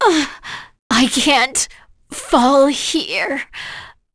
Demia-Vox_Dead.wav